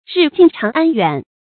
日近長安遠 注音： ㄖㄧˋ ㄐㄧㄣˋ ㄔㄤˊ ㄢ ㄧㄨㄢˇ 讀音讀法： 意思解釋： 長安：西安，古都城名，后為國都的統稱。